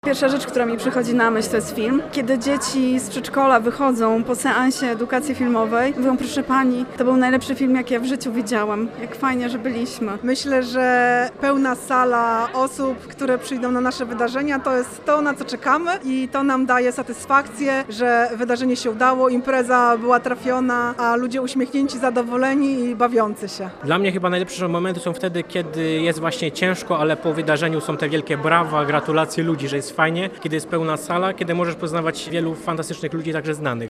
Wieloletnich pracowników Centrum Kultury i Sztuki w Tczewie zapytaliśmy o ich ulubione momenty z pracy.